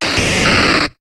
Cri de Drascore dans Pokémon HOME.